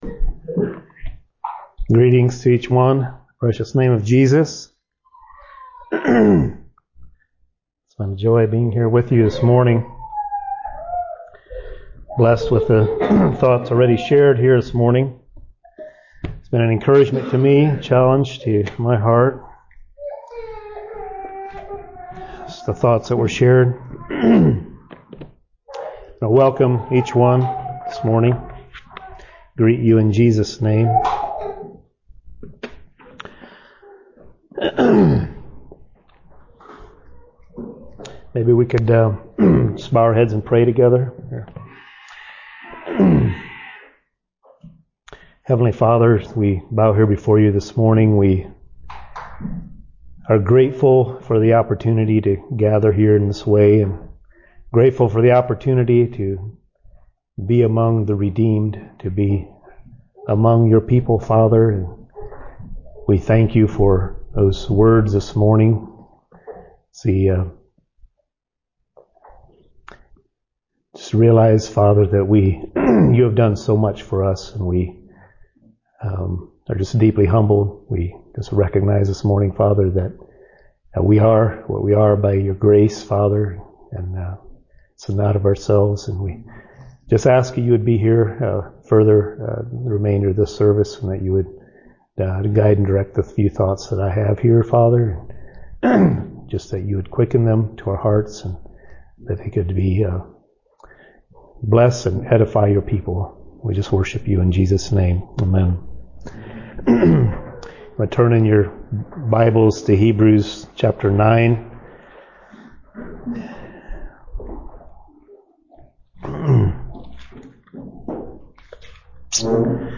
Listen to and download sermons preached in 2025 from Shelbyville Christian Fellowship.